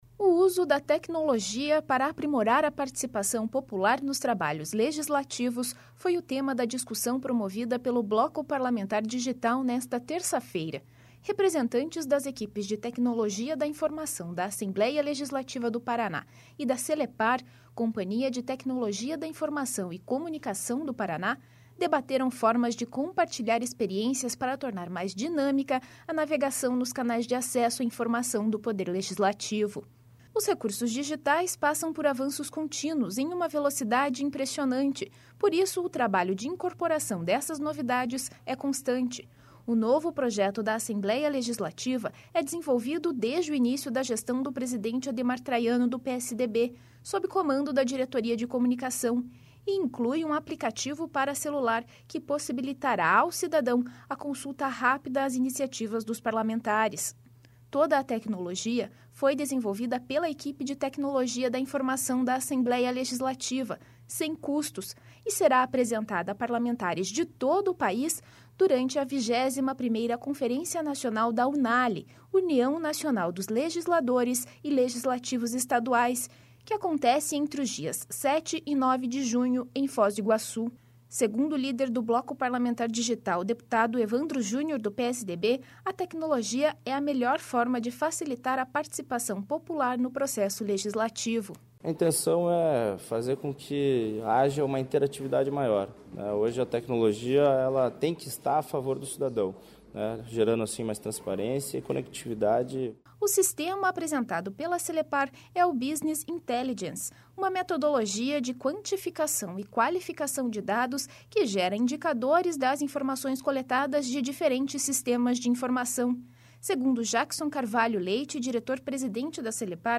Segundo o líder do Bloco Parlamentar Digital, deputado Evandro Júnior (PSDB), a tecnologia é a melhor forma de facilitar a participação popular no processo legislativo.
BG